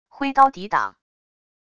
挥刀抵挡wav音频